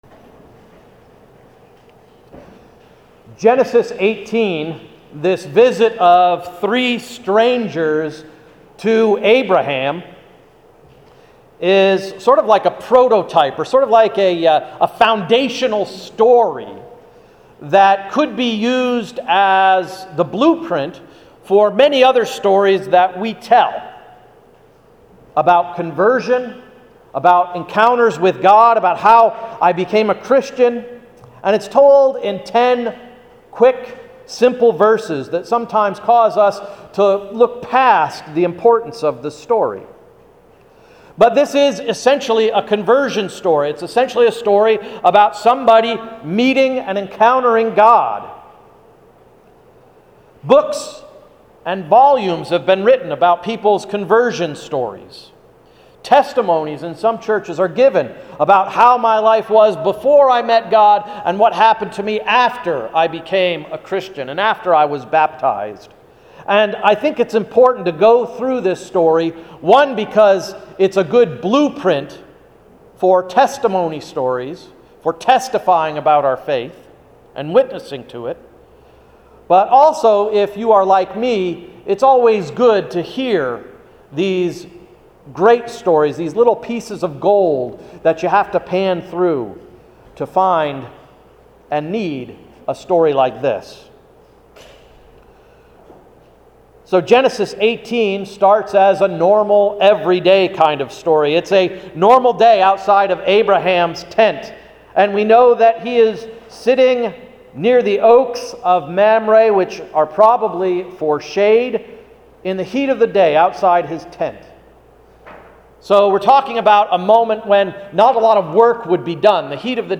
Sermon of July 21–“The Welcome Wagon” – Emmanuel Reformed Church of the United Church of Christ